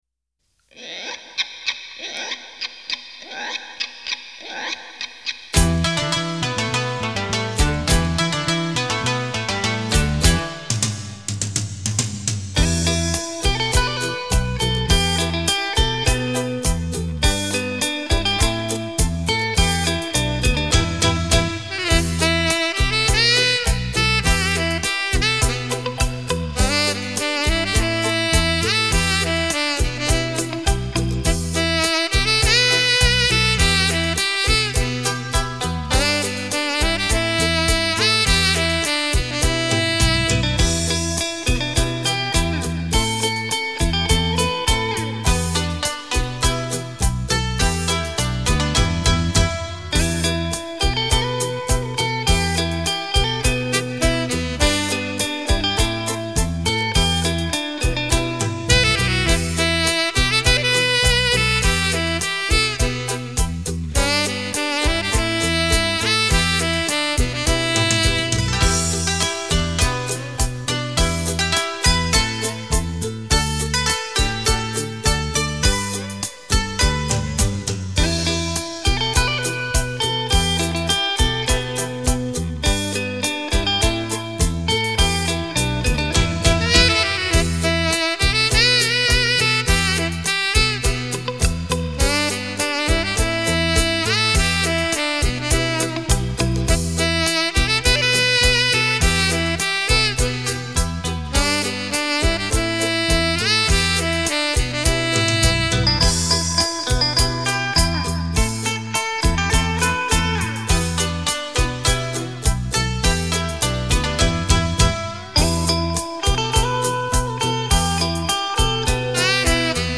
浪漫的台语旋律，醉人心府的萨克斯演奏，感谢版主分享。
都是旋律优美的调子，值得期待的系列完结日，谢谢大大的分享！